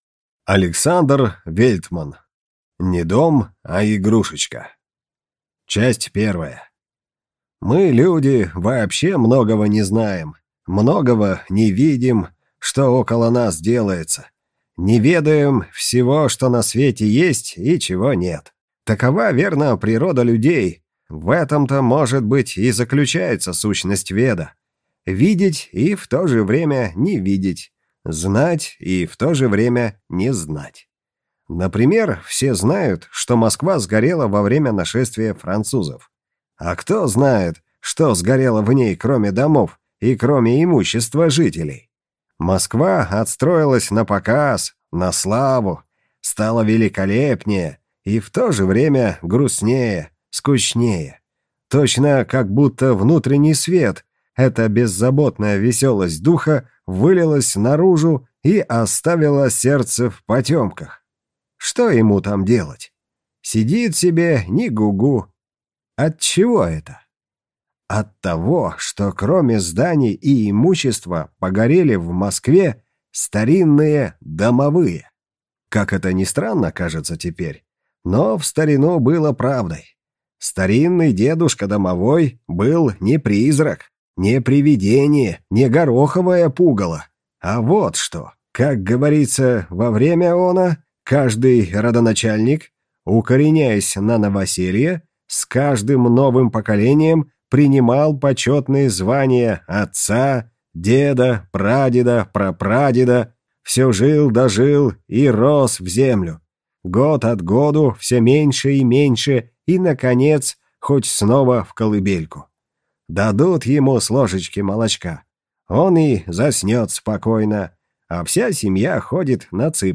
Аудиокнига Не дом, а игрушечка | Библиотека аудиокниг